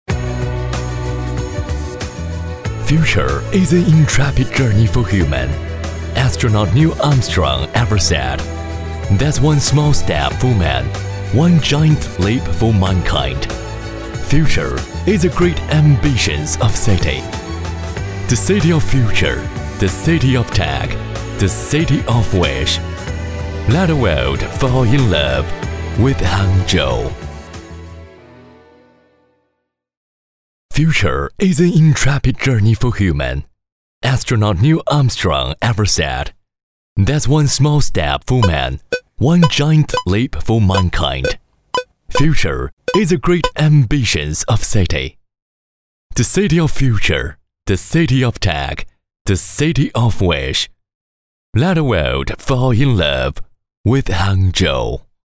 男43号配音师
声音庄重、浑厚、大气，擅长中英双语。
英文-男43-【科技穿越】.mp3